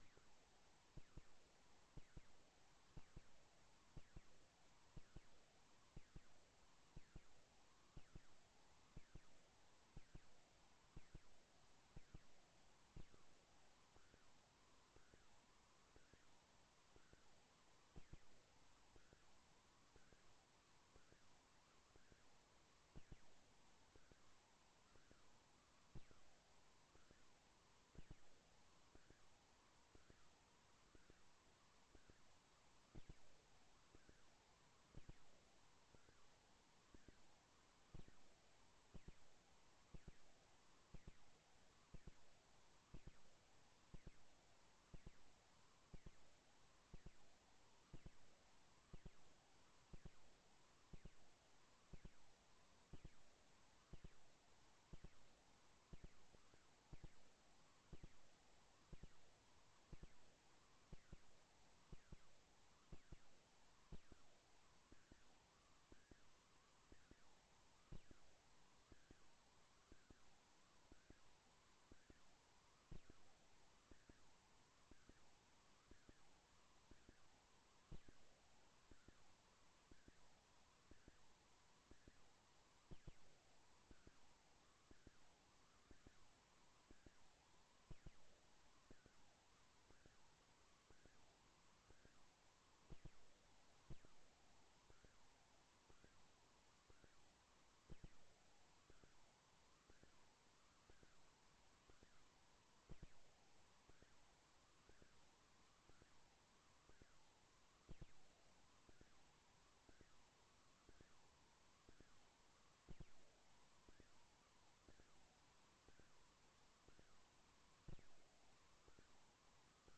Sermon Archives | Aspen Ridge Church